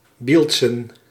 Bilzen (Dutch pronunciation: [ˈbɪlzə(n)]
Nl-Bilzen.ogg.mp3